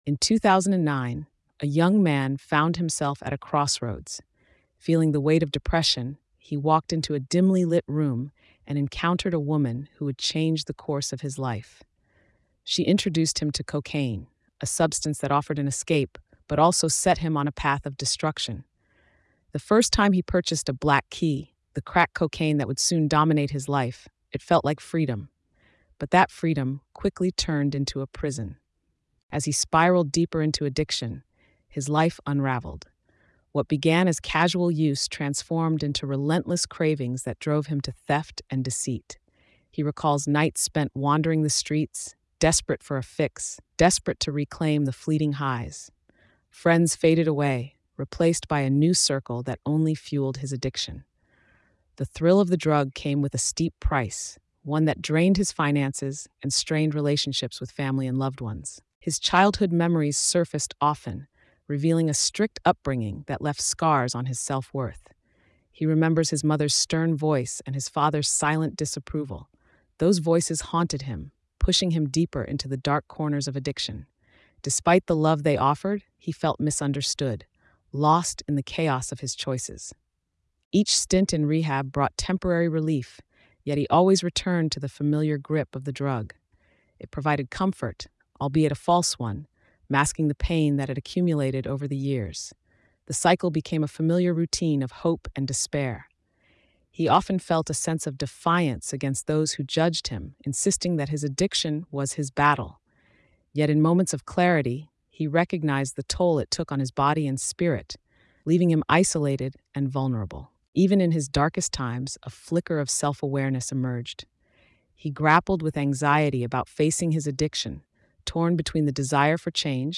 This documentary delivers a raw, unfiltered, never-before-seen look at the crack epidemic in Barbados. Through intimate interviews and on-the-ground access, it follows the lives of Barbadians battling active addiction and those fighting their way through recovery.